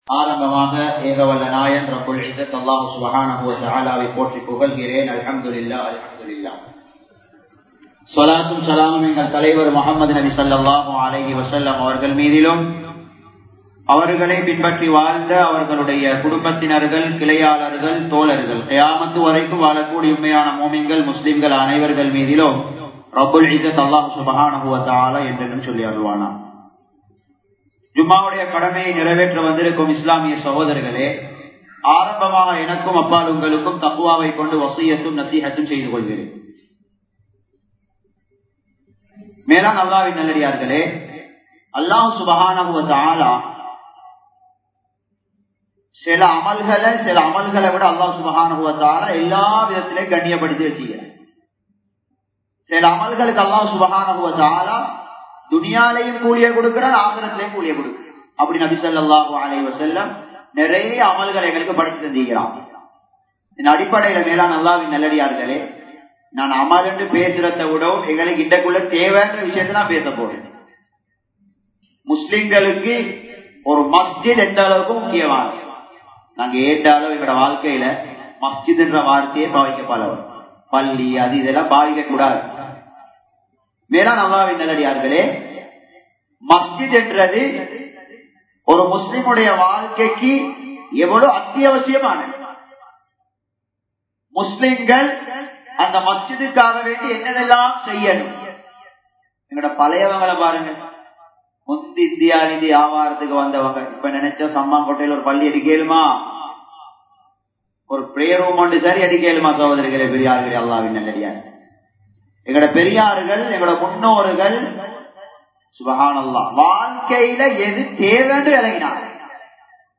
அல்லாஹ்வின் பக்கத்து வீட்டார்கள் | Audio Bayans | All Ceylon Muslim Youth Community | Addalaichenai